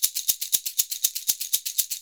Index of /90_sSampleCDs/USB Soundscan vol.56 - Modern Percussion Loops [AKAI] 1CD/Partition D/04-SHAKER119